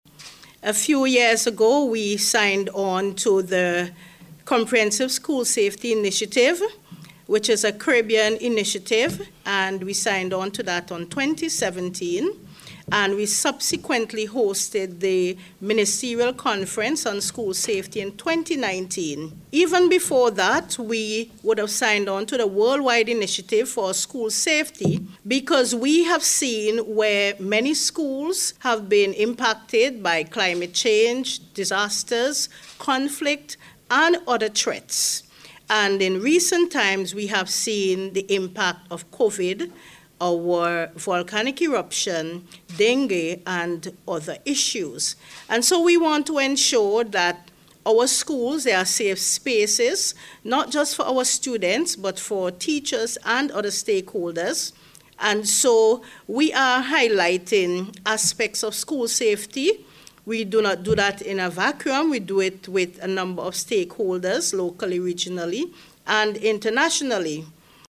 The Ministry held a Media Launch yesterday at the Conference Room of the Kingstown Public Library, to mark the start of the activities, which are being held with the theme:   Safety for Sustainable Development